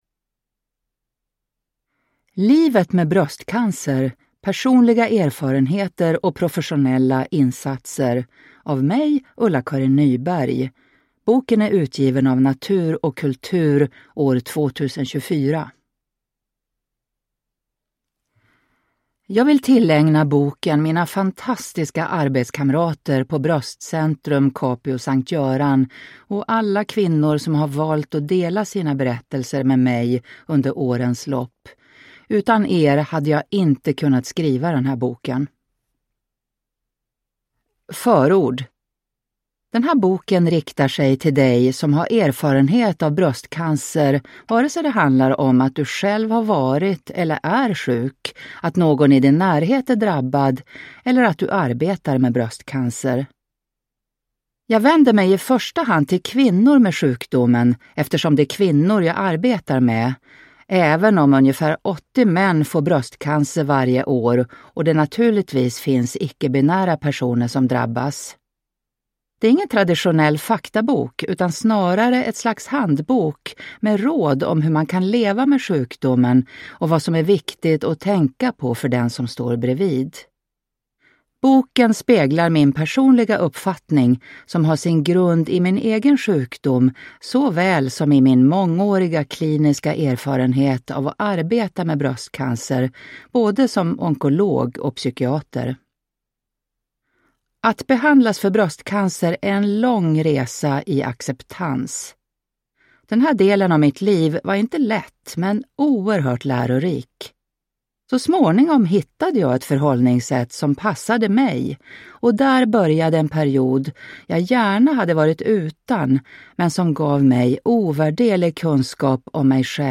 Livet med bröstcancer : personliga erfarenheter och professionella insatser – Ljudbok